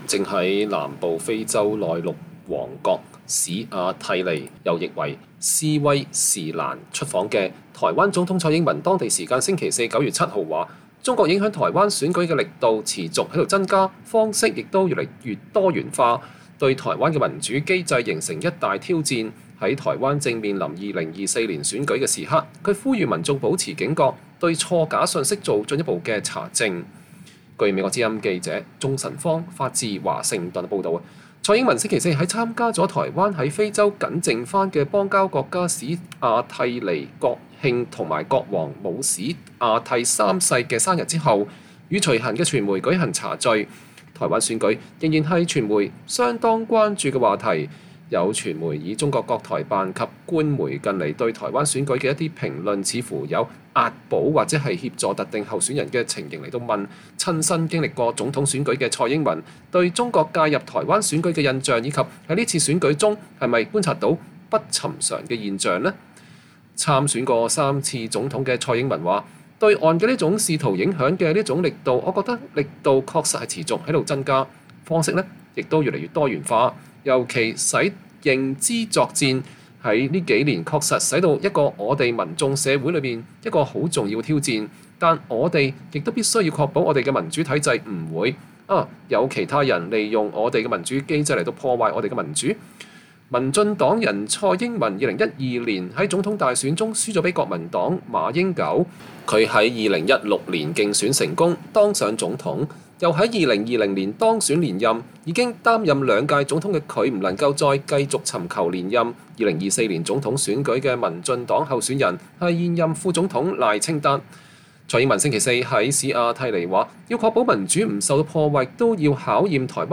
台灣總統蔡英文出訪非洲邦交國家斯威士蘭（又譯史瓦帝尼)時與隨行媒體茶敘。(2023年9月7日，台灣總統府提供）